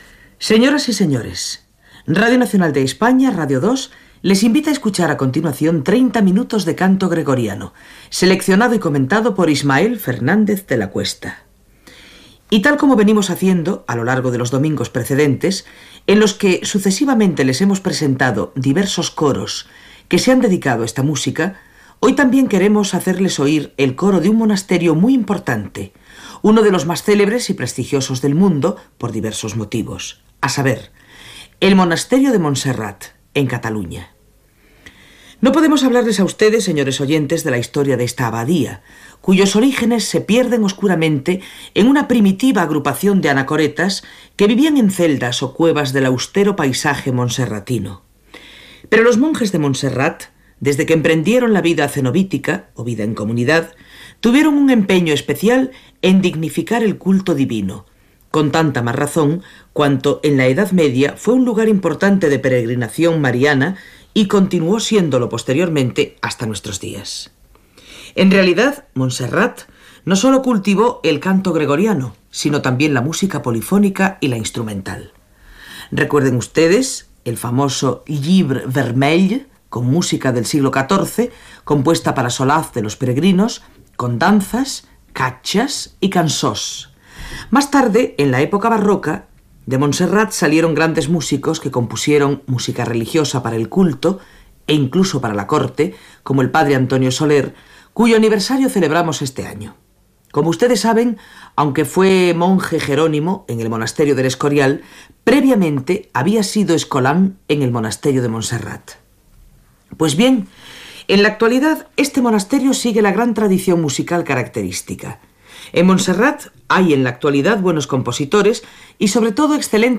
Presentació i espai dedicat al cant gregorià dels monjos del Monestir de Montserrat
Musical